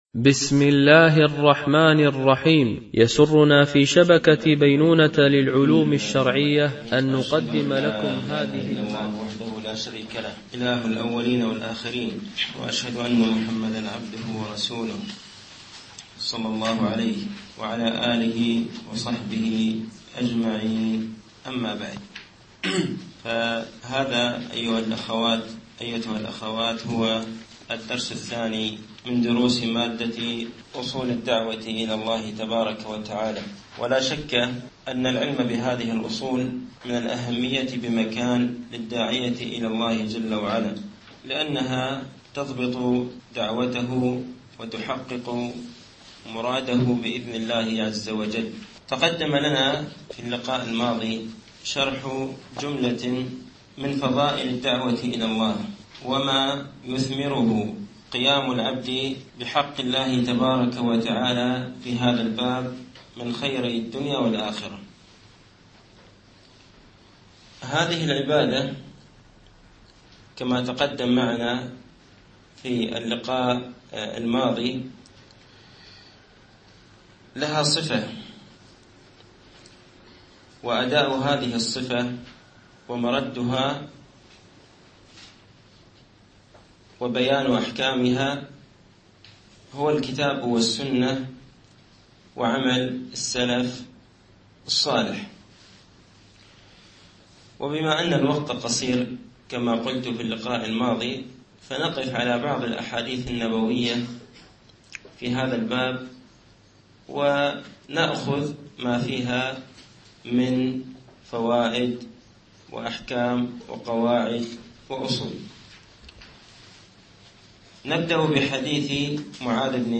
فقه أصول الدعوة إلى الله تعالى - الدرس الثاني